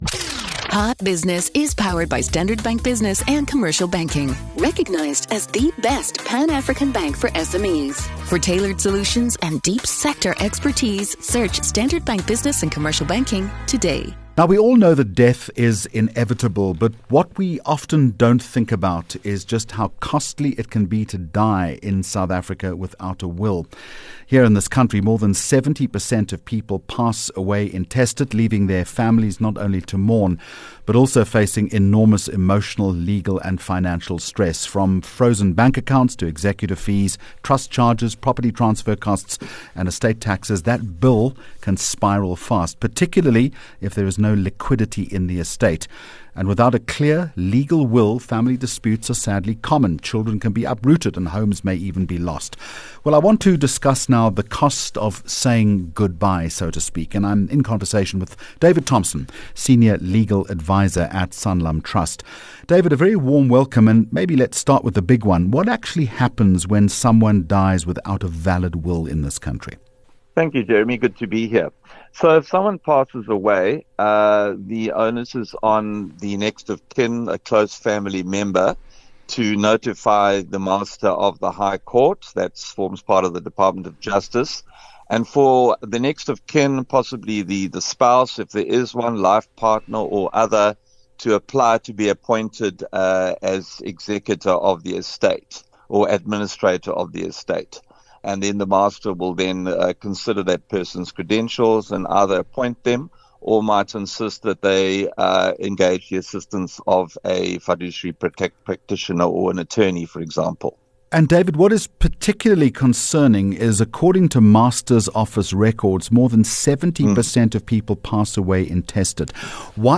Expert Interview Topic: The cost of dying without a will Guest